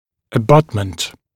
[ə’bʌtmənt][э’батмэнт]абатмент; опорный зуб (для мостовидного протеза)
abutment.mp3